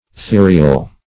therial - definition of therial - synonyms, pronunciation, spelling from Free Dictionary Search Result for " therial" : The Collaborative International Dictionary of English v.0.48: Therial \The"ri*al\, a. Theriac.
therial.mp3